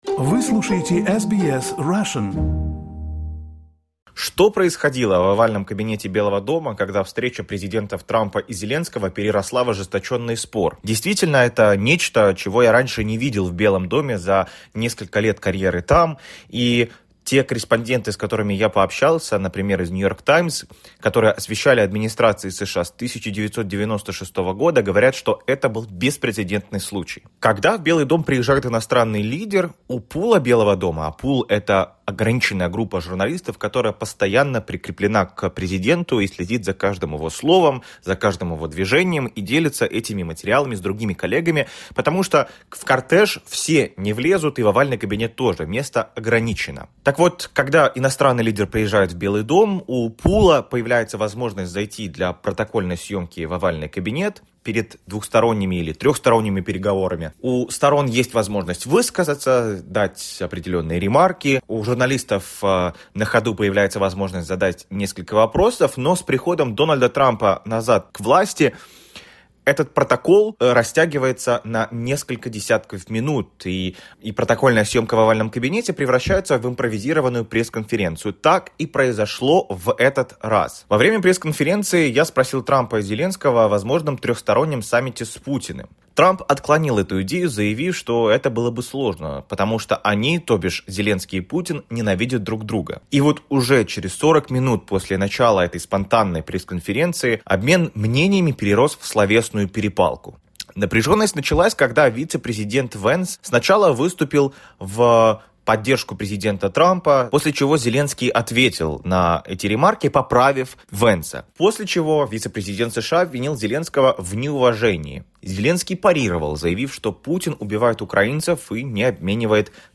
Слушайте в репортаже из Белого дома.